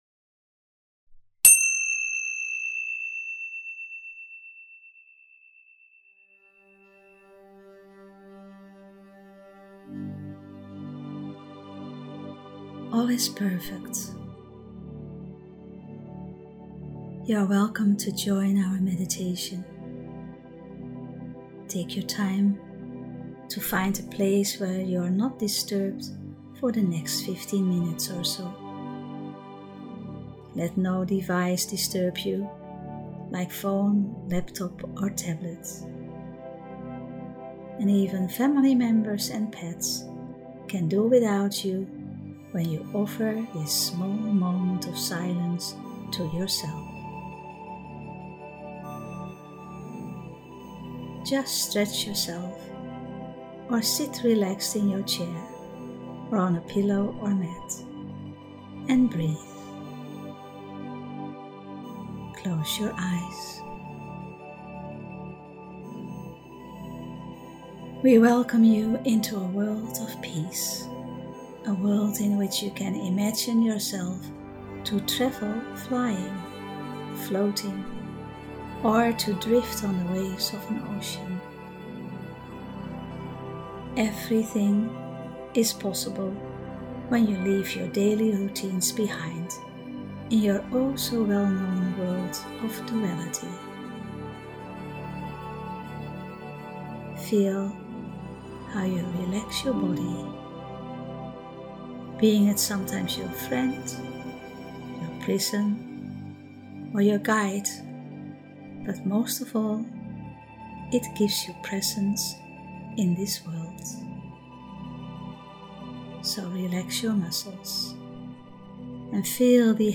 Meditation “All is Perfect…”